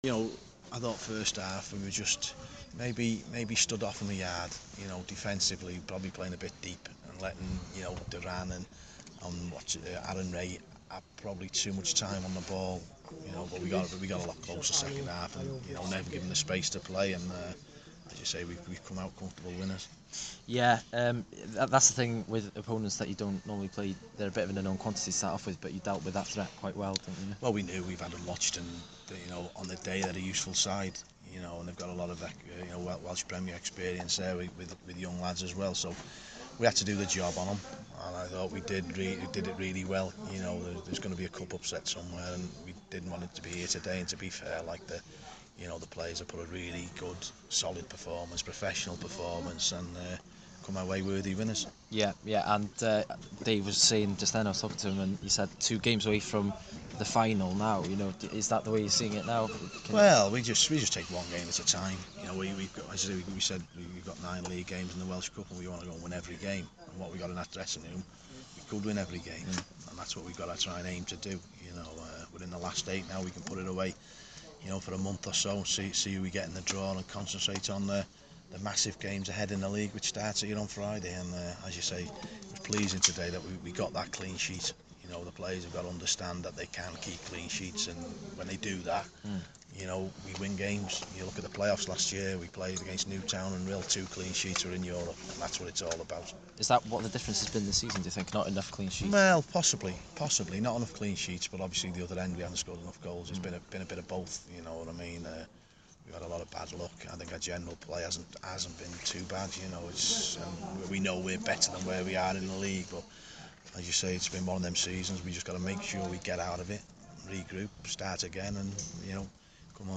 is interviewed following the 3-1 victory over Conwy Borough in the Welsh Cup